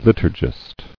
[lit·ur·gist]